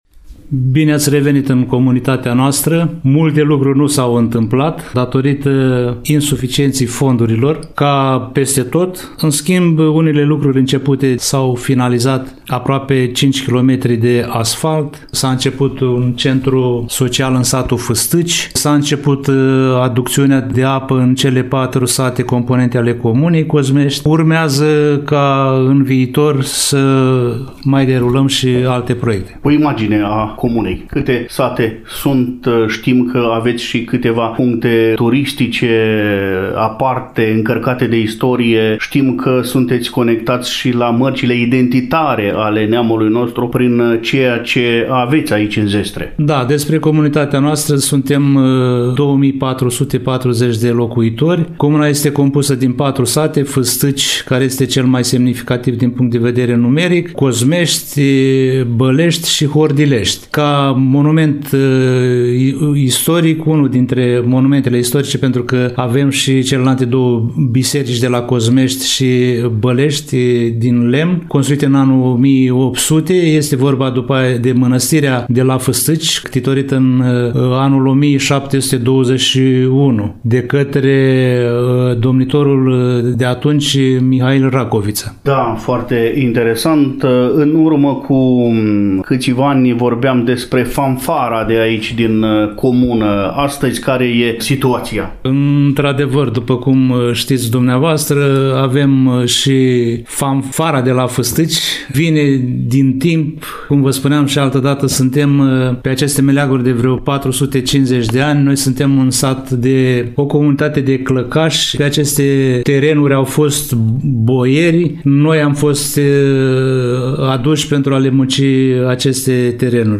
Primul interlocutor al emisiunii este domnul Hristache Sima, edilul șef al comunei Cozmești.
A_Sima-Hristache-dialog-6-25.mp3